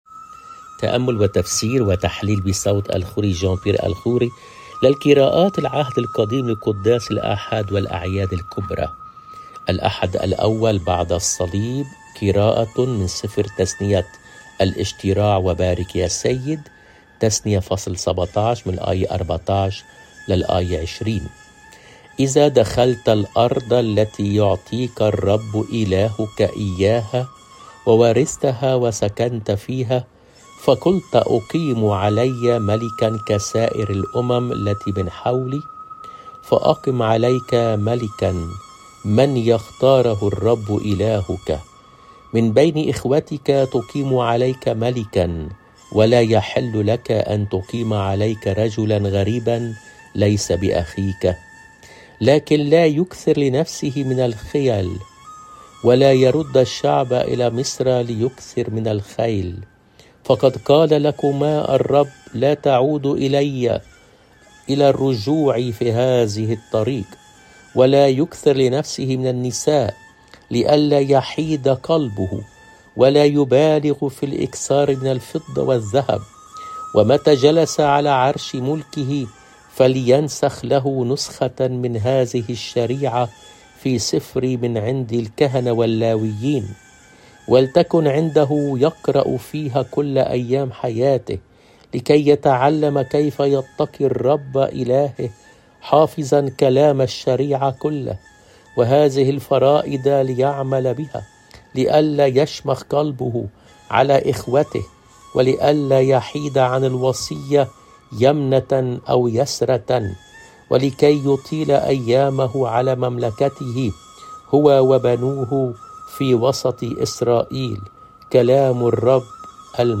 قراءة من العهد القديم